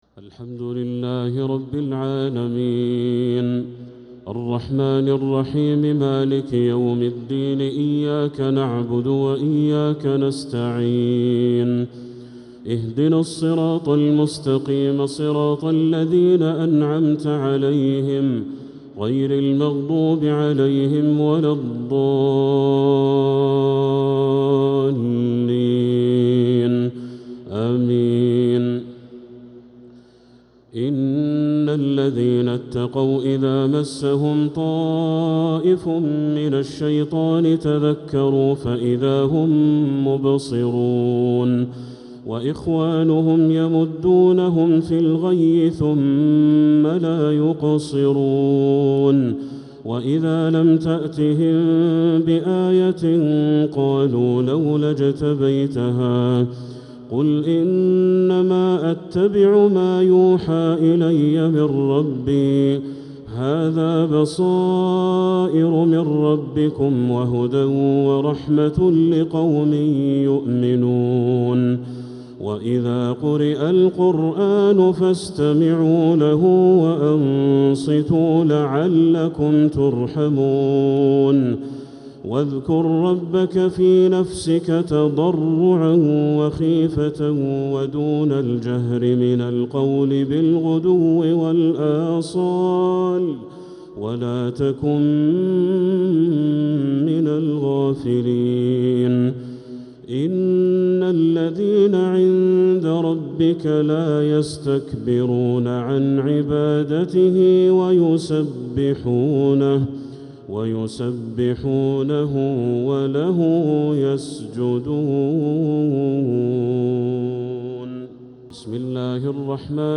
تراويح ليلة 12 رمضان 1446هـ من سورتي الأعراف (201-206) و الأنفال (1-40) | taraweeh 12th niqht Surat Al-Araf and Al-Anfal 1446H > تراويح الحرم المكي عام 1446 🕋 > التراويح - تلاوات الحرمين